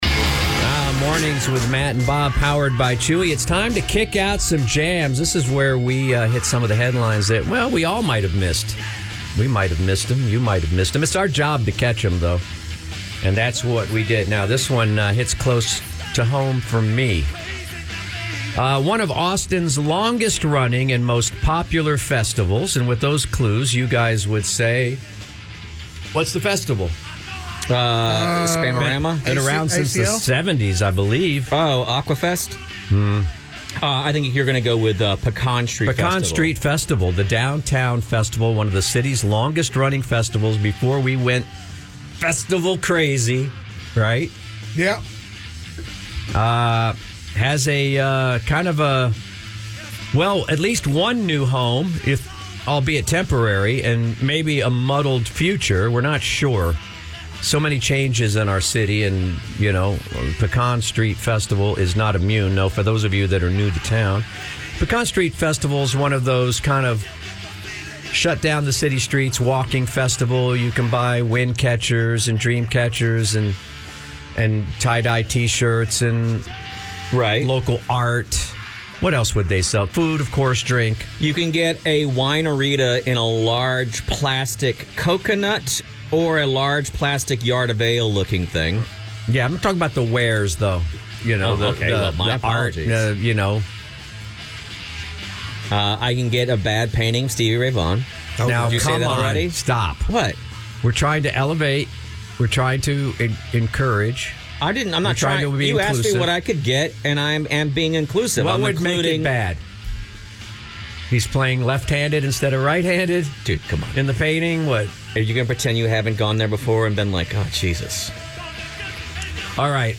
The fellas chat about changes coming to the 50th Annual Pecan Street Festival coming up this spring during Kick Out the Jams this morning.